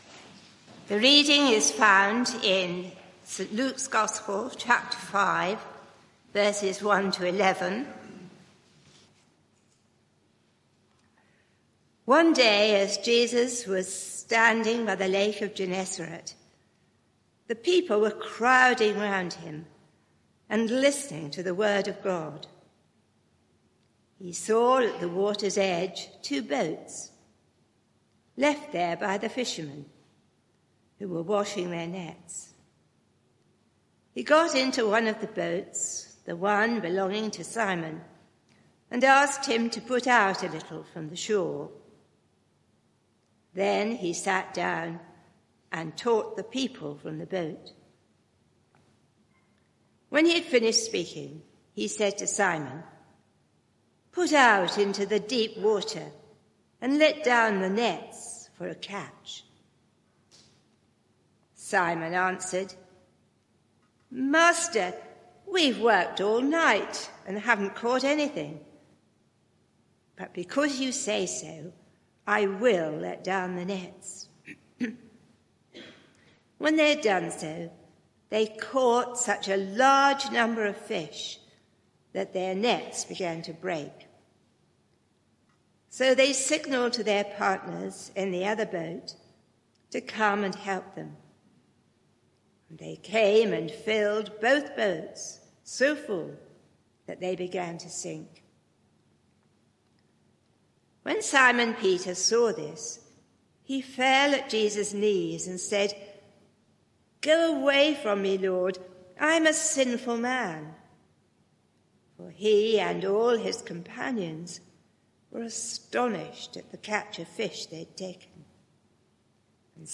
Media for 11am Service on Sun 19th Jan 2025 11:00 Speaker
Sermon (audio) Search the media library There are recordings here going back several years.